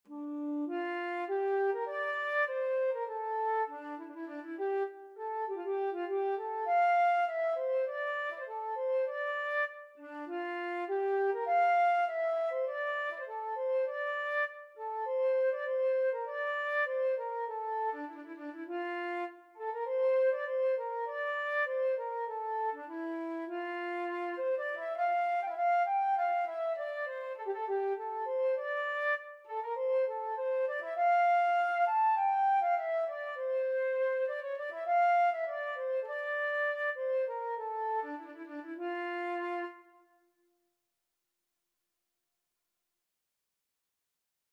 Traditional Trad. Good Health to my Maid (Irish Folk Song) Flute version
4/4 (View more 4/4 Music)
D minor (Sounding Pitch) (View more D minor Music for Flute )
Instrument:
Flute  (View more Easy Flute Music)
Traditional (View more Traditional Flute Music)